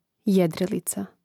jȅdrilica jedrilica